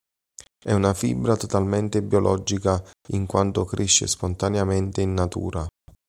Read more fiber, fibre (all senses) Frequency C2 Hyphenated as fì‧bra Pronounced as (IPA) /ˈfi.bra/ Etymology Derived from Latin fibra.